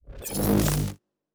Weapon 05 Load (Laser).wav